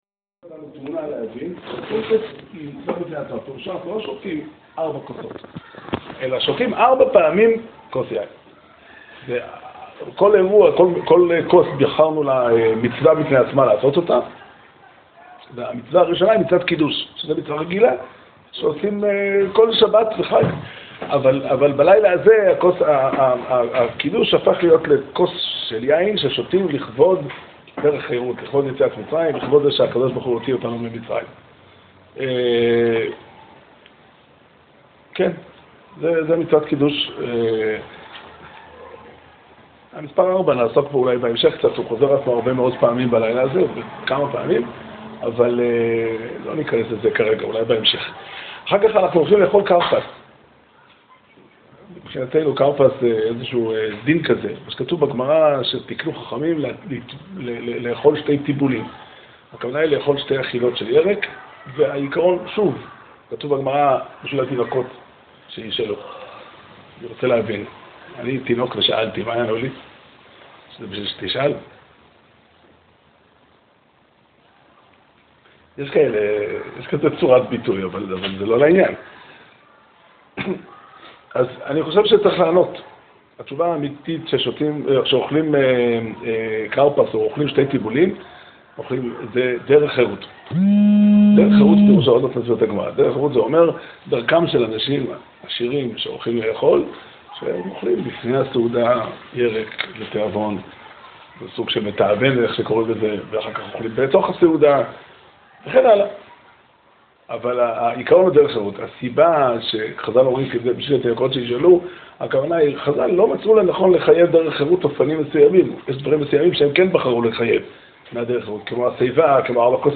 שיעור שנמסר בבית המדרש 'פתחי עולם' בתאריך כ"ד אדר תשע"ח